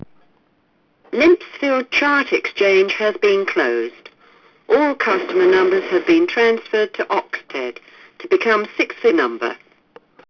Limpsfield Chart Exchange closure, Found lurking on an Announcer 12A in the depths of Toll B 223k